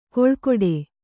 ♪ koḷkoḍe